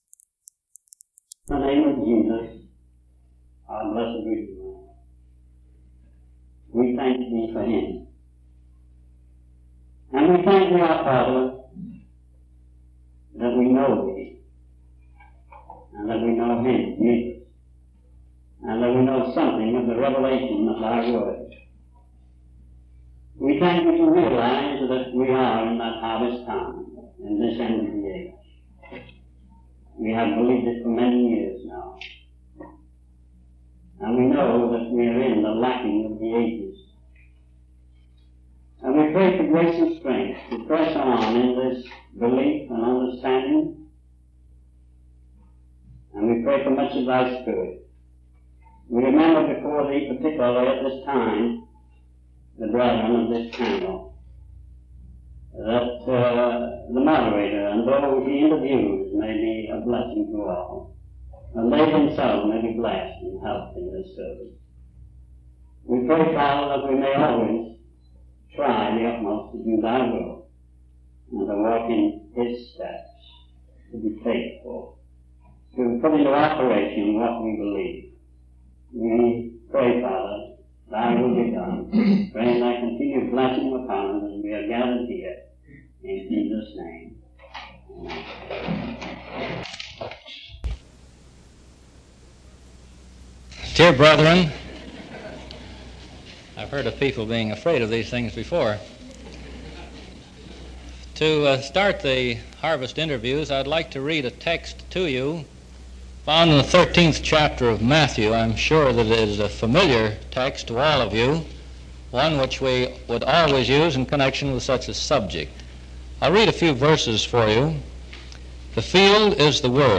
From Type: "Vesper Service"